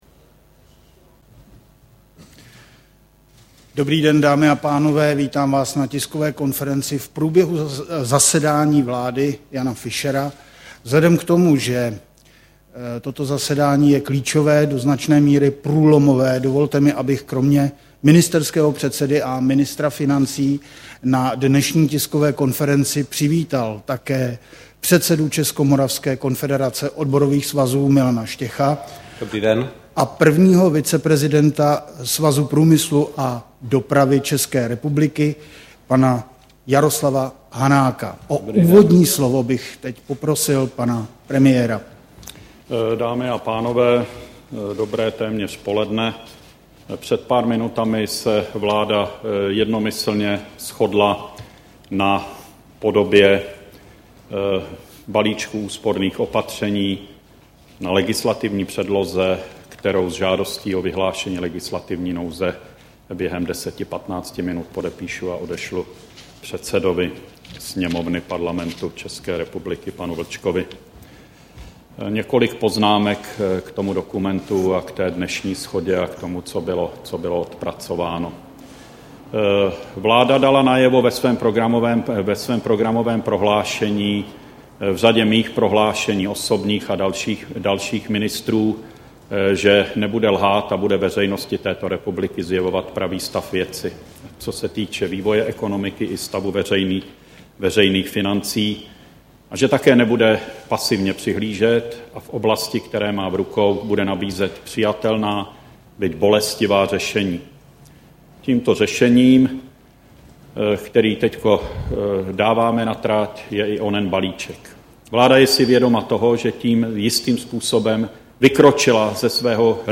Zvuková příloha Tisková konference po zasedání vlády, 21. září 2009 Tisková konference po zasedání vlády, 21. září 2009 flv • 10016 kB